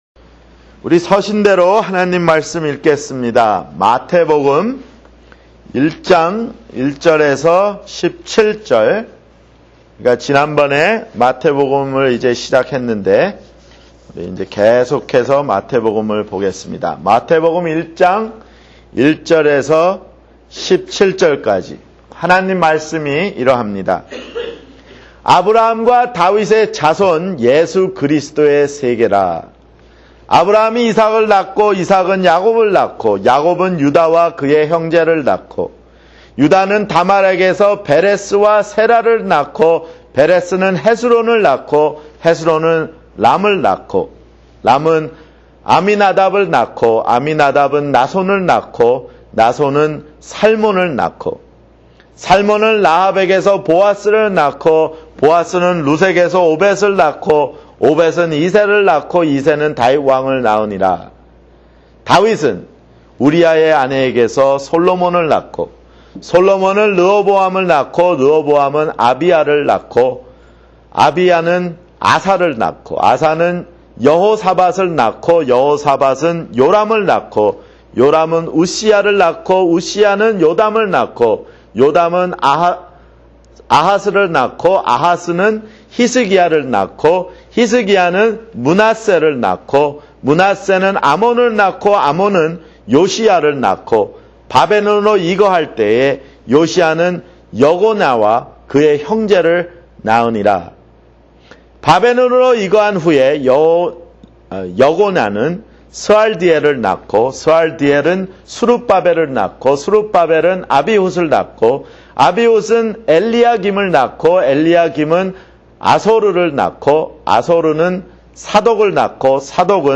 [주일설교] 마태복음 (2)